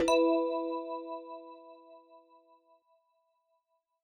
retweet_send.ogg